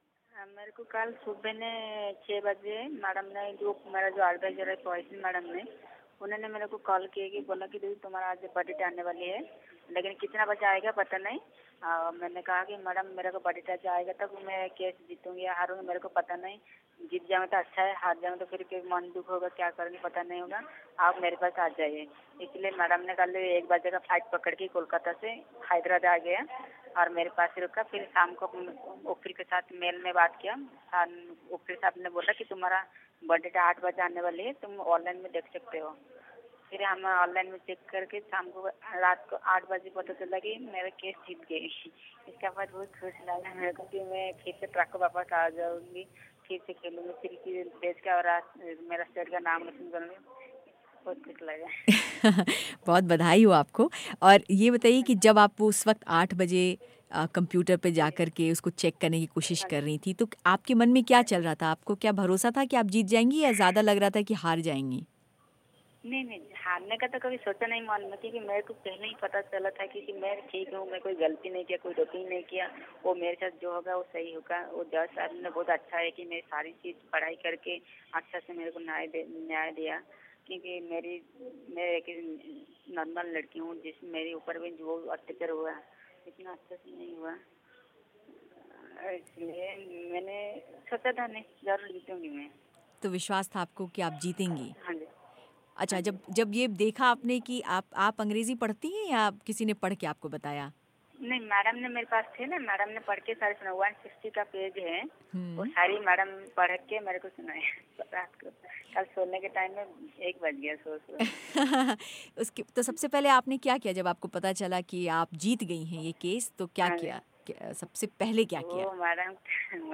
उनकी बातचीत